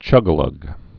(chŭgə-lŭg) Slang